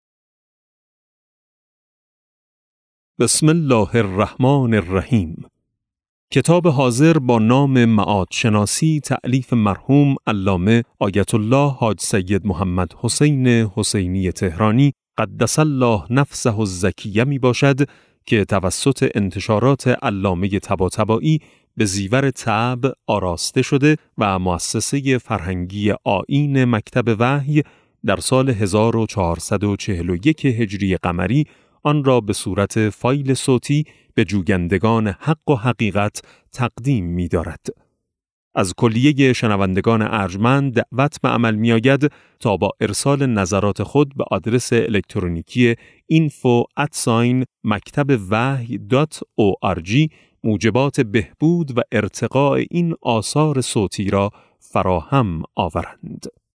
کتاب صوتی معاد شناسی ج8 - جلسه0